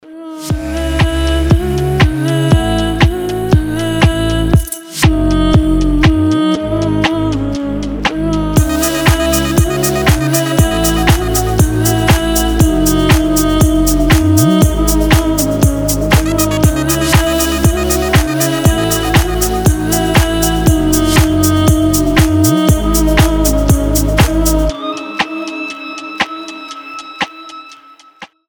• Качество: 320, Stereo
deep house
атмосферные